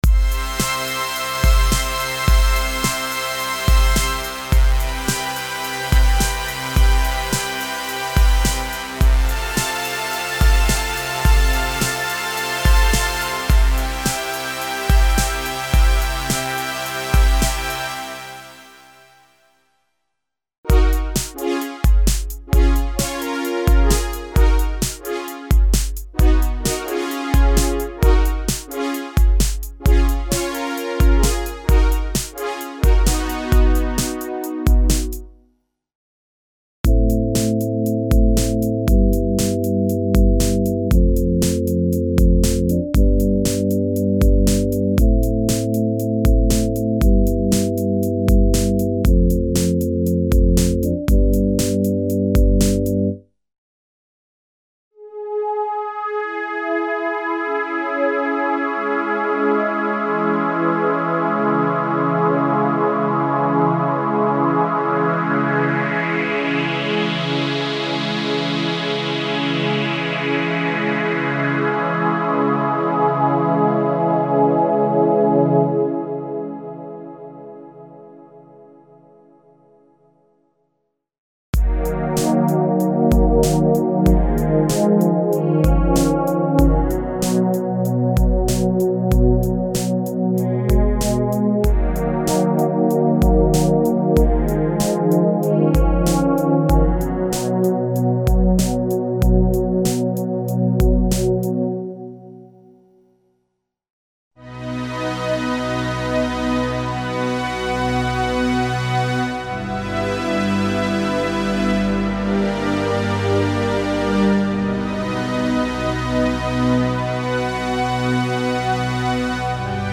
Emulations of vintage analog synthesizers (warm and sharp synth pads & sweeps).
Info: All original K:Works sound programs use internal Kurzweil K2500 ROM samples exclusively, there are no external samples used.